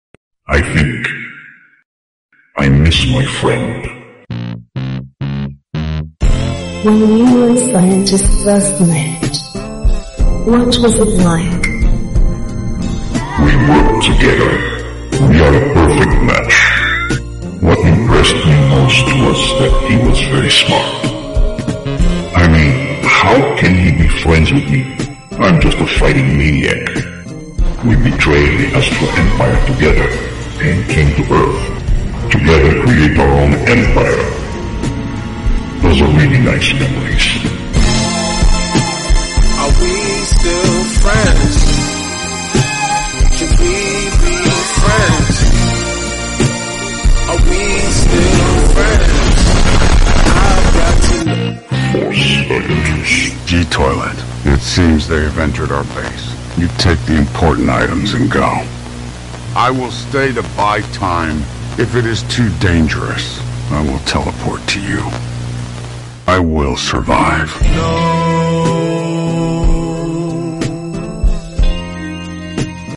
tried to make G Toilet voice sound effects free download